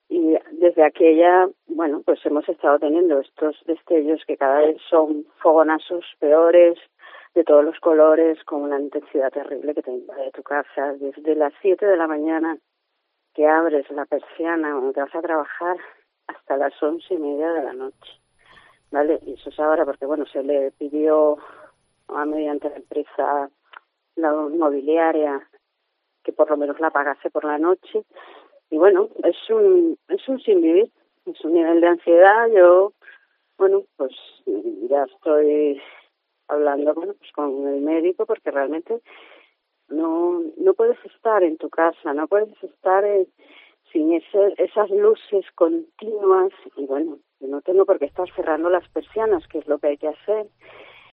Una vecina del Hórreo denuncia la contaminación lumínica que padece